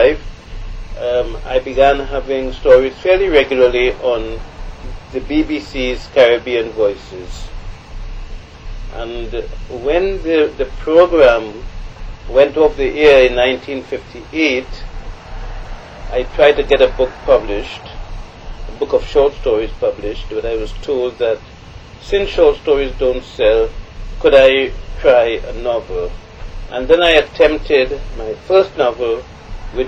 3 audio cassettes
The Oral and Pictorial Records Programme (OPReP)